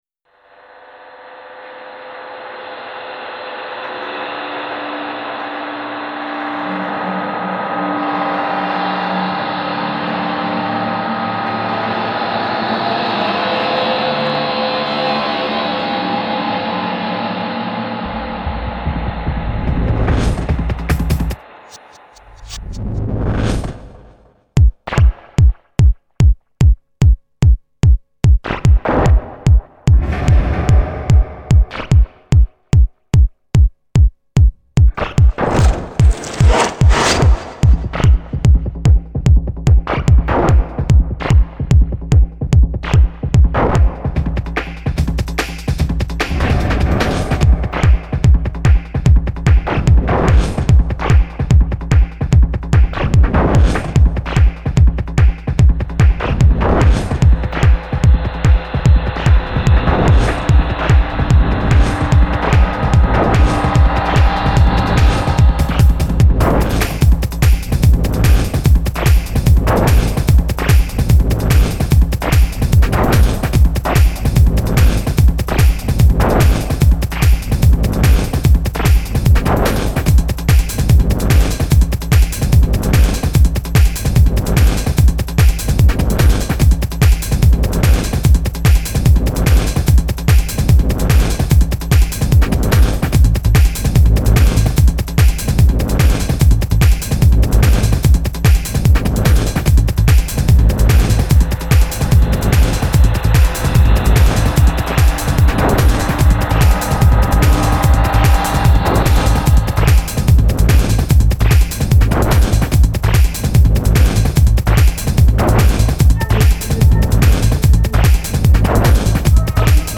Жанр: TECHNO